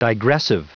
Prononciation du mot digressive en anglais (fichier audio)